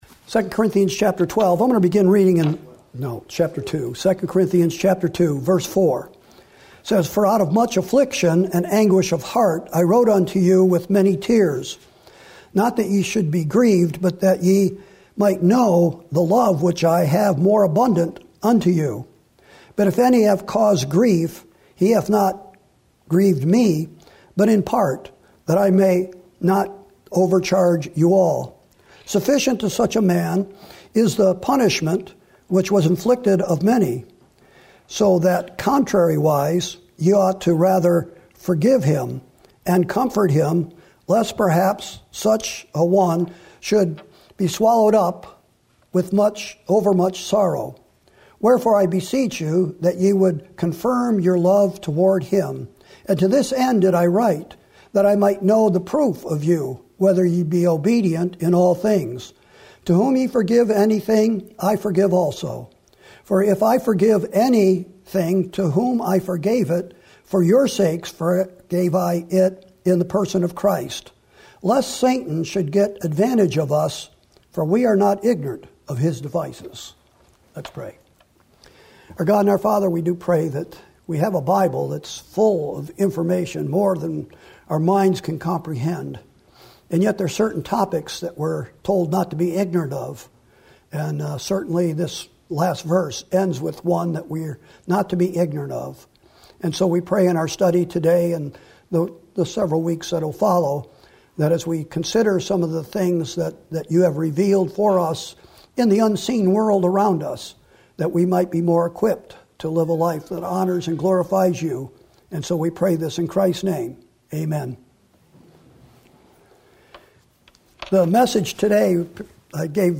Sermons & Single Studies